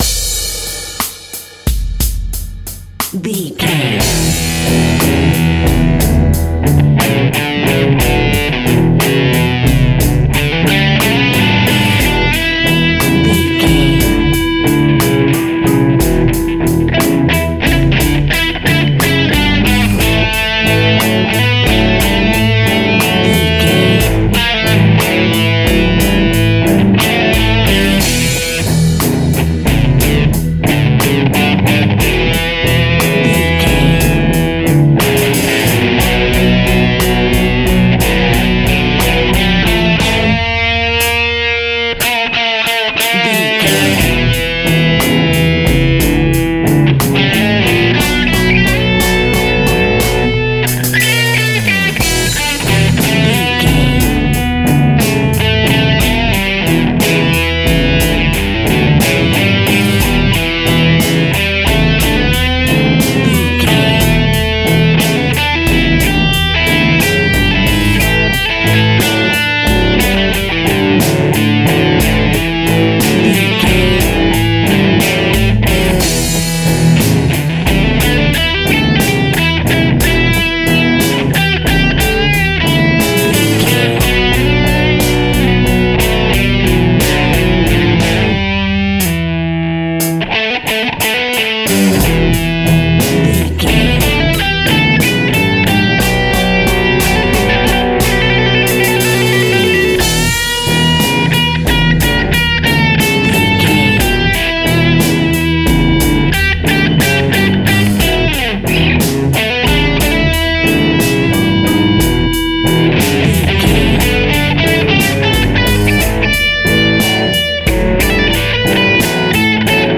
Aeolian/Minor
drums
electric guitar